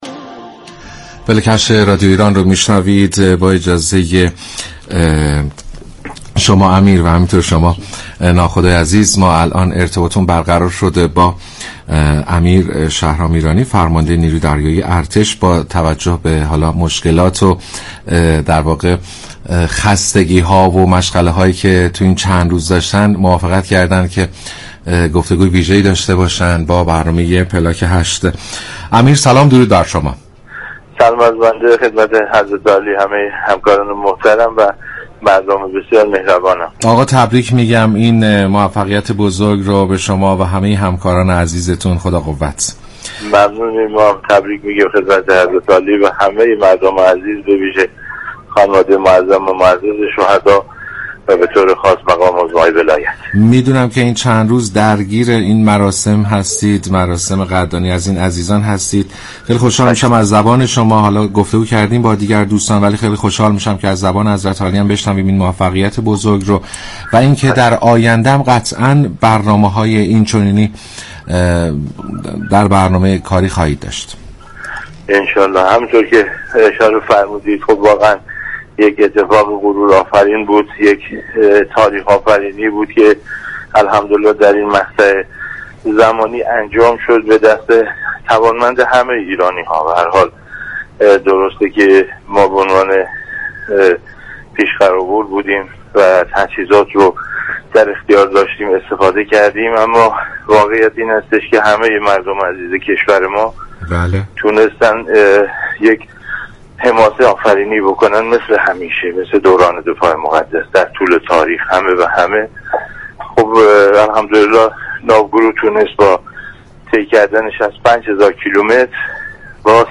به گزارش شبكه رادیویی ایران، امیر شهرام ایرانی فرمانده نیروی دریایی ارتش در برنامه پلاك هشت رادیو ایران به موفقیت ناوگروه 86 نیروی دریایی ارتش در سفر به دور دنیا پرداخت و گفت: سفر موفقیت آمیز ناوگروه 86 نیروی دریایی ارتش در سفر به دور دنیا در این مقطع زمانی یك اتفاق تاریخی بود كه به دست توانمند همه ایرانیان به انجام رسید.